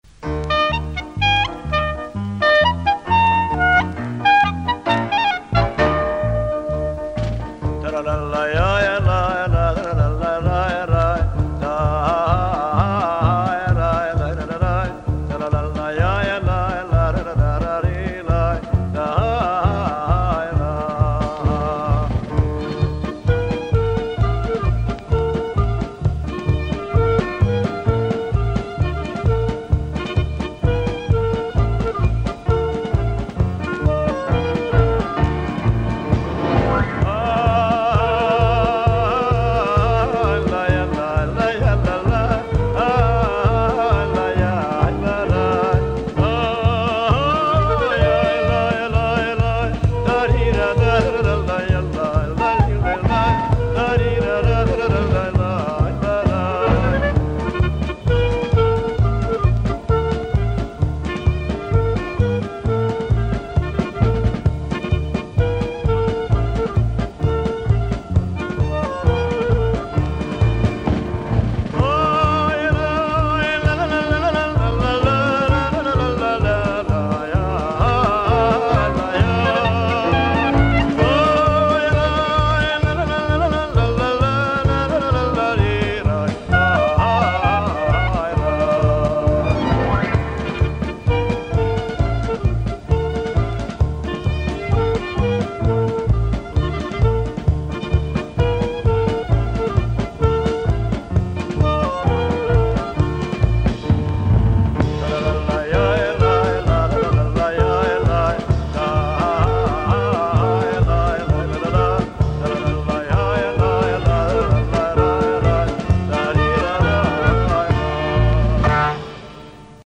ניגון זה הוא ניגון שמחה לר' אהרן חריטונוב מניקולייב והושר בהתוועדויות הרבי.